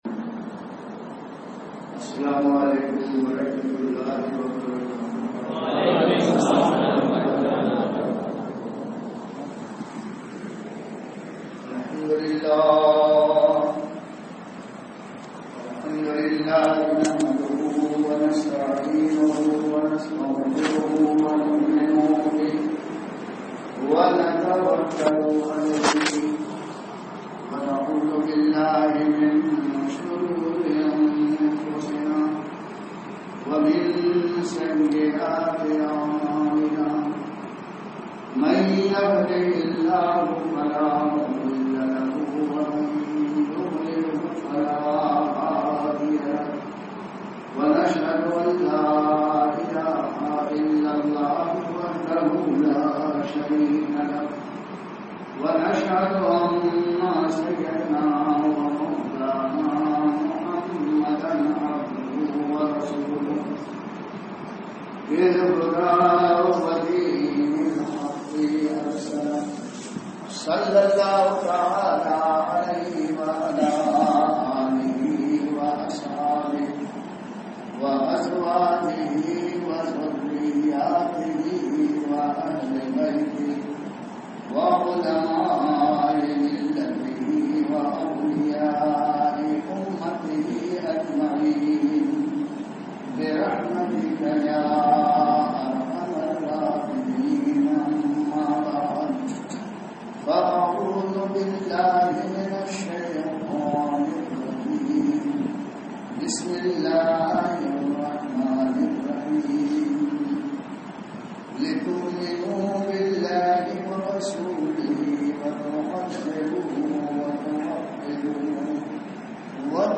Speeches
Jama Masjid, AnkaPalle, A.P.
Juma-Bayaan-Ankapalle-2018.mp3